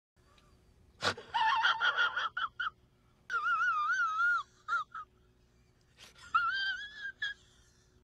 PLAY Zach Galifianakis Laugh